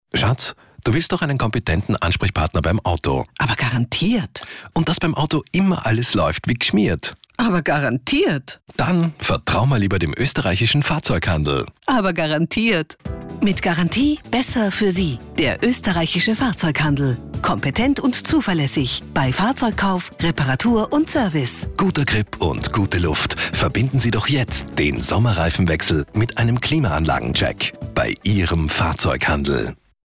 Bei uns hören Sie schon jetzt den neuen Werbespot.